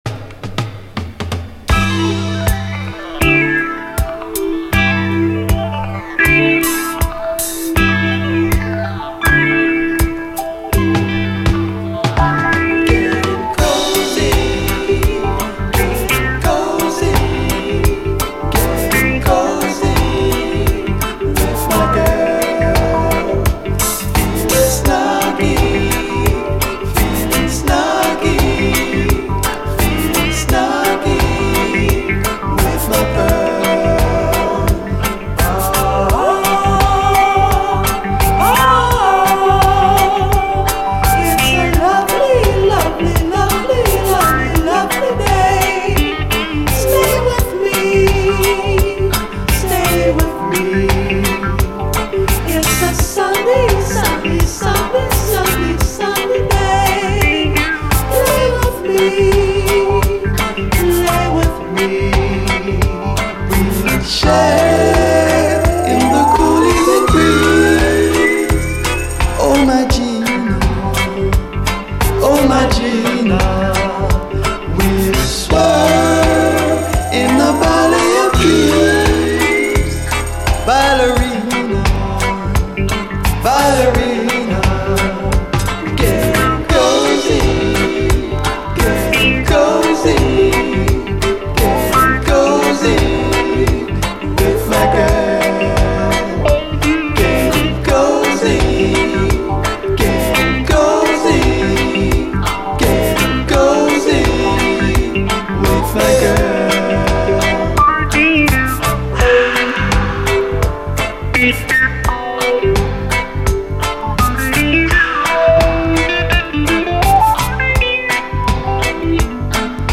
A面に２本ヘアラインありますが音には全く出ず、両面プレイ良好
明るく弾む